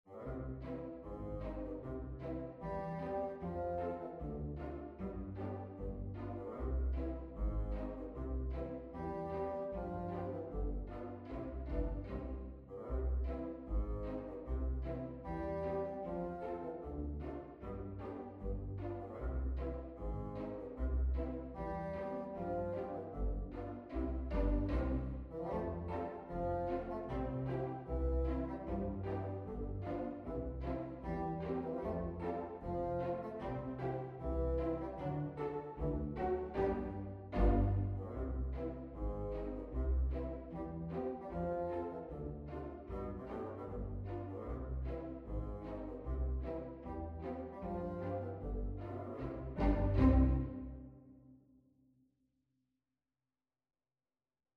MUSIC FOR FAILS for bassoon and pizzicato strings
I made certain that the bumbling melody and accompaniment are always bumping into each other. This is to reflect the world in which we live where people post videos of themselves falling down stairs, for example.